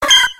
infinitefusion-e18 / Audio / SE / Cries / CHIKORITA.ogg